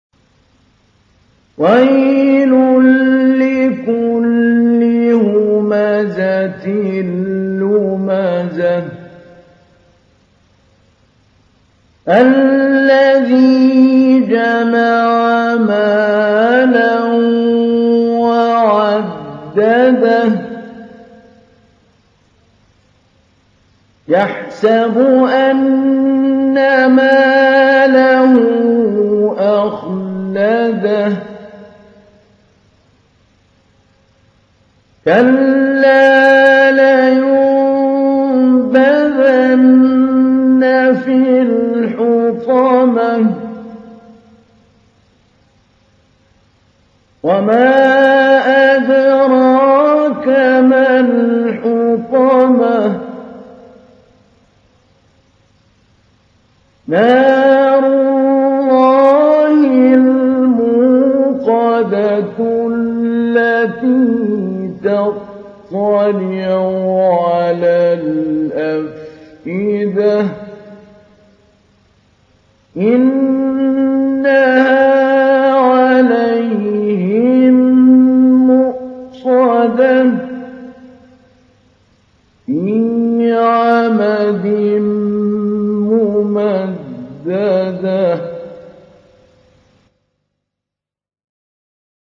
تحميل : 104. سورة الهمزة / القارئ محمود علي البنا / القرآن الكريم / موقع يا حسين